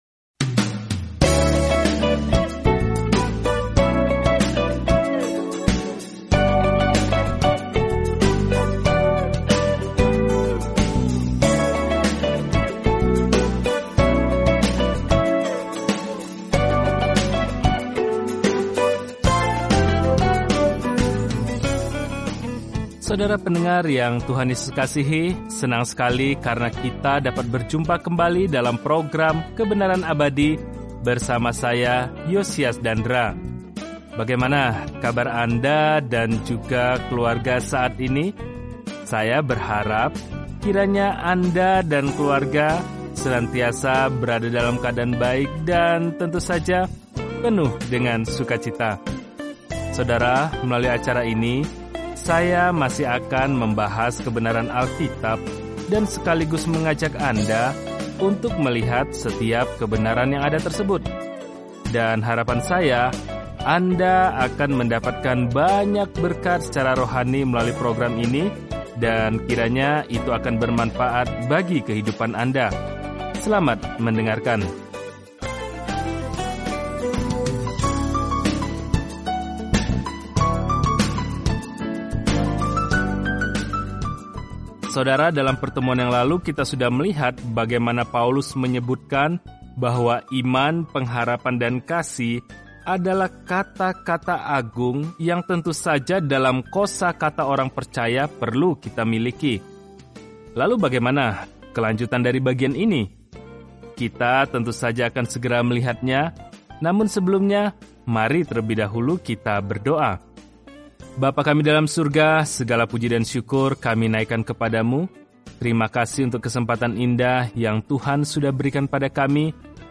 Telusuri 1 Korintus setiap hari sambil mendengarkan pelajaran audio dan membaca ayat-ayat tertentu dari firman Tuhan.